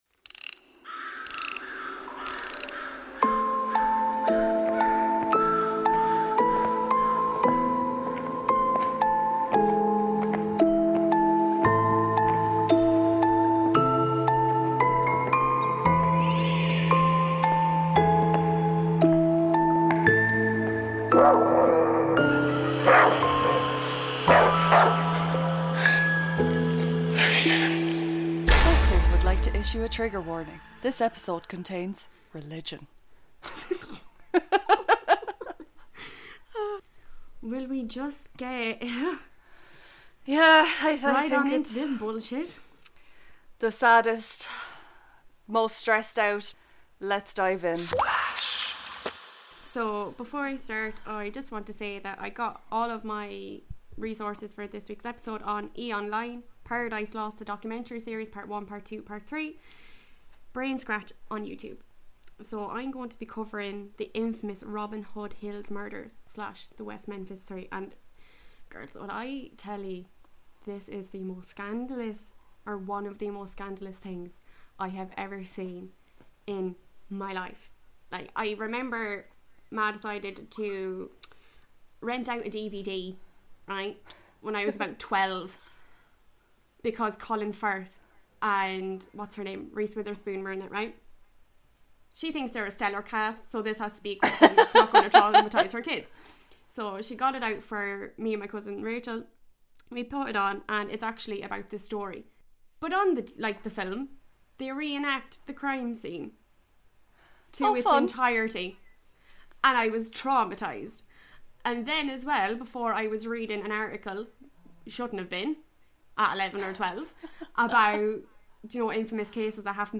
*PSA* There's some slight audio issues with this weeks episode